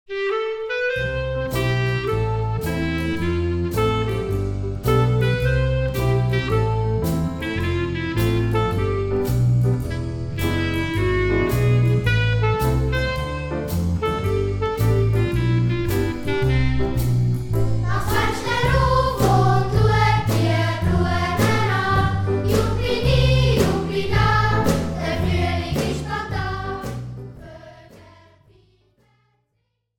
Besetzung: Gesang